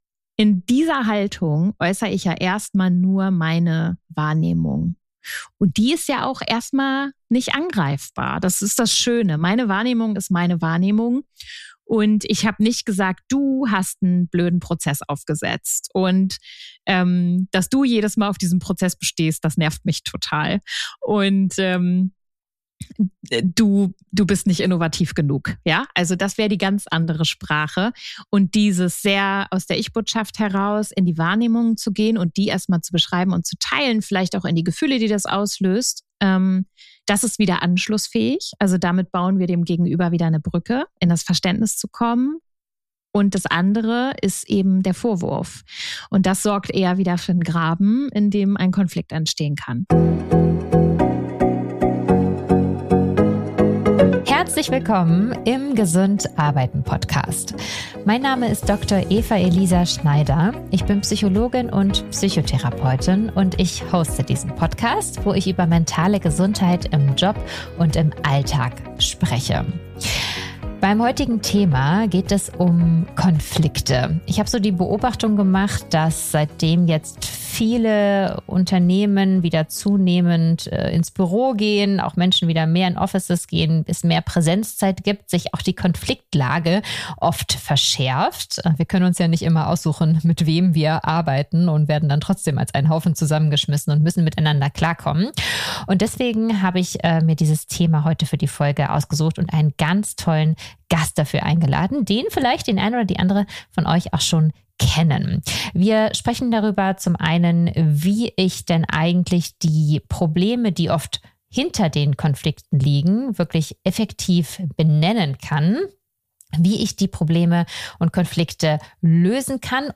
Ganz besonders sprechen wir darüber, wie man Lösungen finden kann, ohne die emotionale Ebene zu übergehen und wieder zusammenfindet, wenn es Verletzungen gab. Im Interview klären wir außerdem, wie man es schafft, sich nicht in externe Konflikte verwickeln zu lassen, sei es als Kolleg:in oder als Führungskraft. Du bekommst reichlich Tools, um bessere Gespräche zu führen, Konflikte souverän zu lösen und dich so im Joballtag leichter zu fühlen.